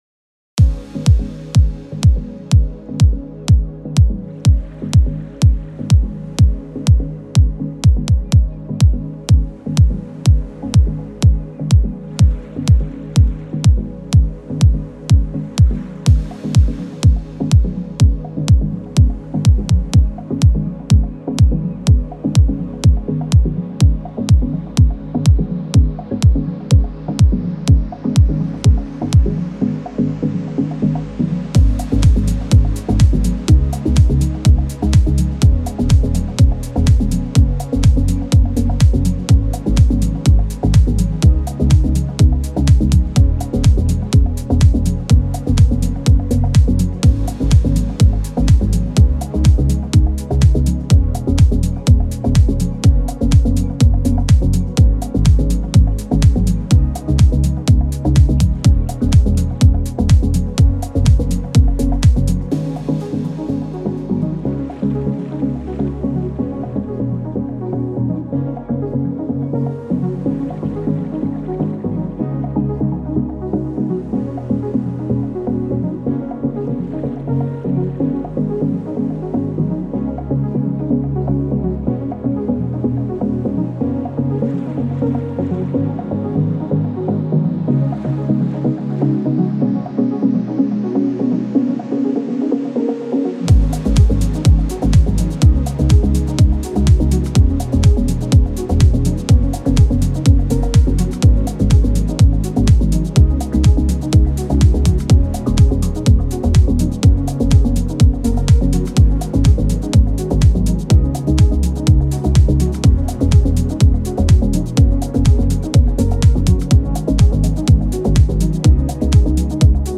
پر‌انرژی
پراگرسیو هاوس
ساکسیفون